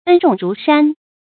恩重如山 ēn zhòng rú shān 成语解释 恩情像高山一样厚重；形容恩义极为深重。
成语简拼 ezrs 成语注音 ㄣ ㄓㄨㄙˋ ㄖㄨˊ ㄕㄢ 常用程度 常用成语 感情色彩 褒义成语 成语用法 主谓式；作谓语、定语；含褒义，用于感激词 成语结构 主谓式成语 产生年代 古代成语 成语正音 重，不能读作“chónɡ”。